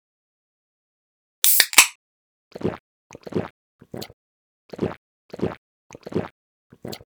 drink_can2.ogg